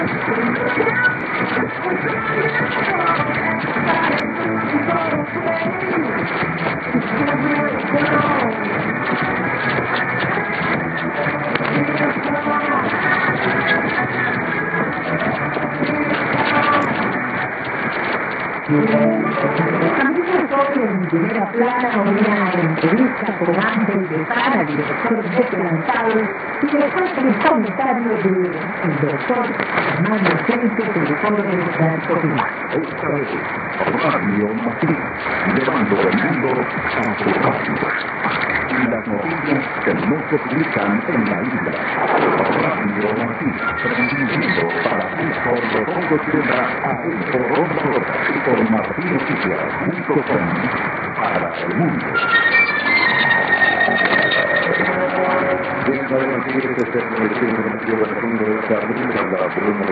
・このＨＰに載ってい音声(ＩＳとＩＤ等)は、当家(POST No. 488-xxxx)愛知県尾張旭市で受信した物です。